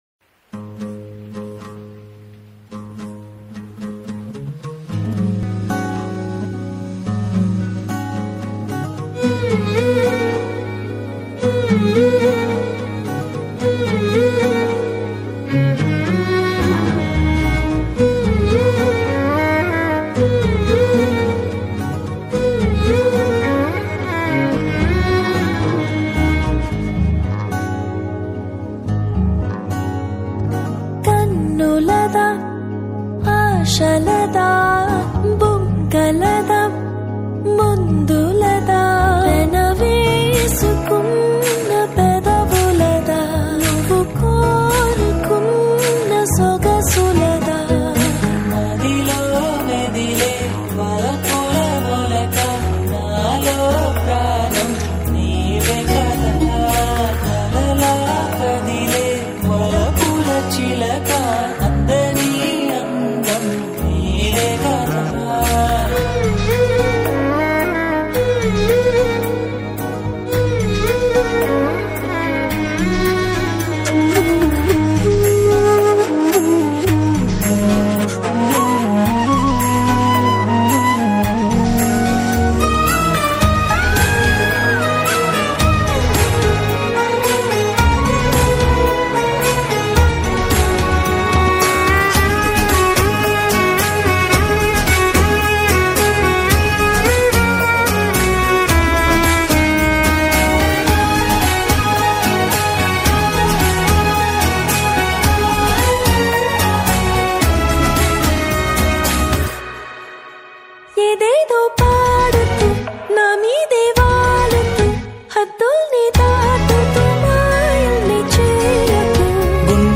All TAMIL LOFI REMIX